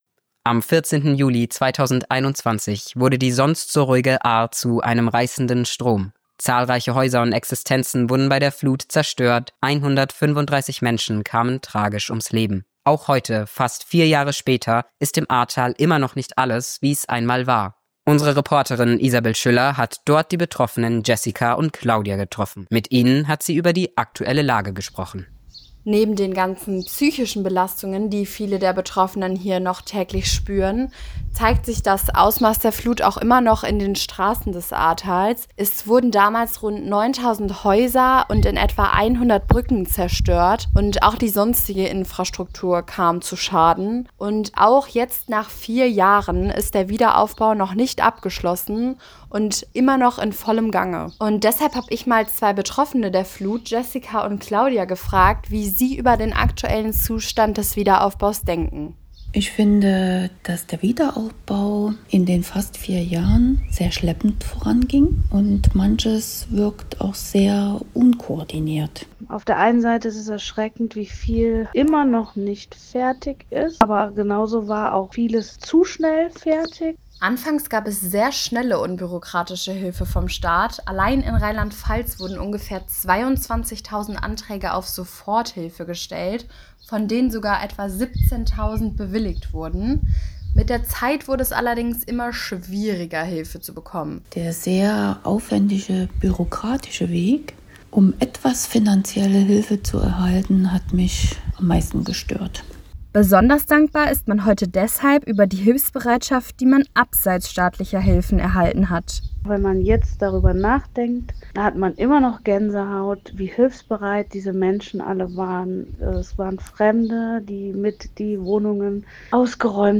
Reportage-Ahrtal-Neu.wav